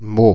Ääntäminen
Ääntäminen France (Paris): IPA: [mo] Haettu sana löytyi näillä lähdekielillä: ranska Käännöksiä ei löytynyt valitulle kohdekielelle.